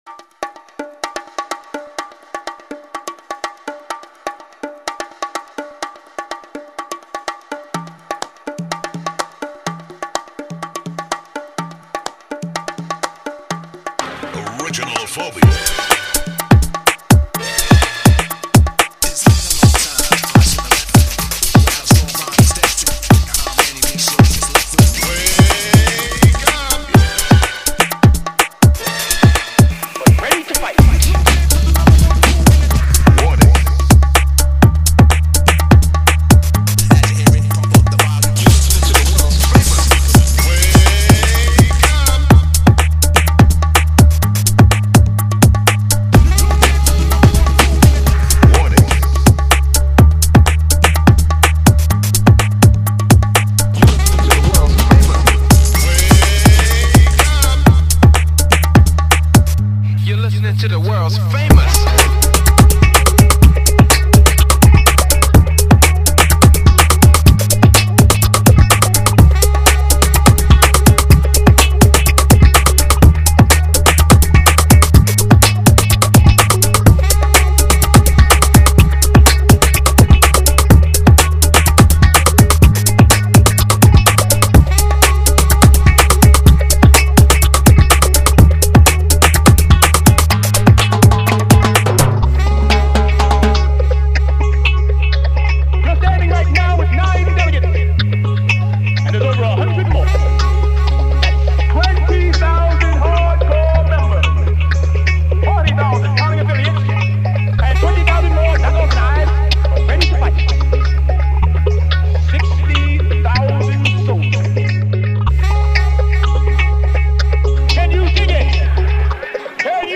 remake
home studio